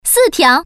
Index of /hunan_master/update/12813/res/sfx/common_woman/